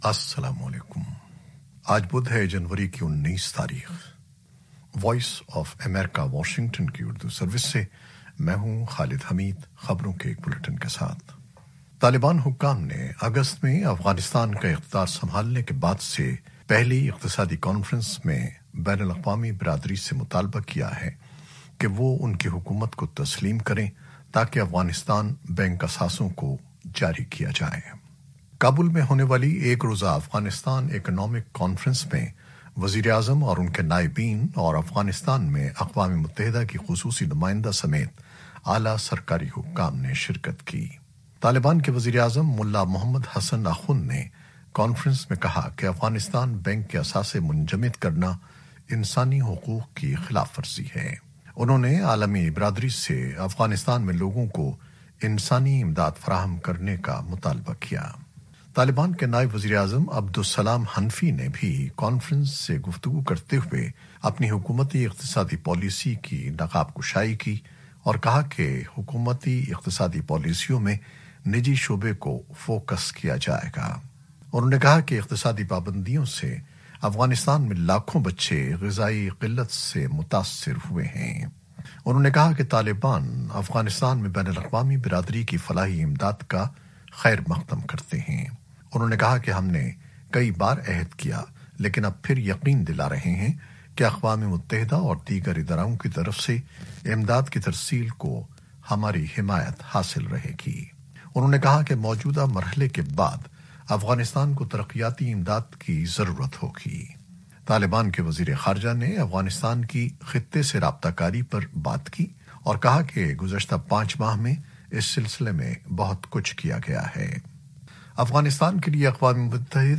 نیوز بلیٹن 2021-19-01